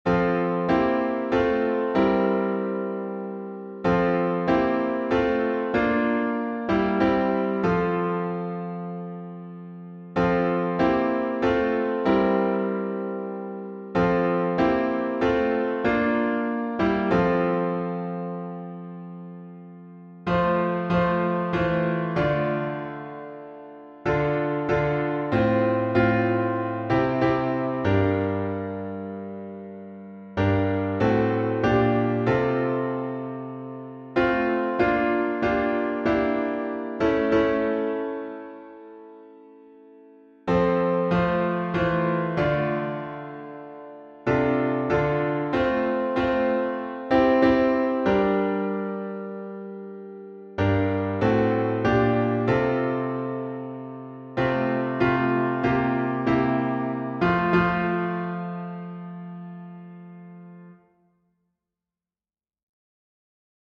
#5042: Be Still My Soul — F Major with 5 stanzas | Mobile Hymns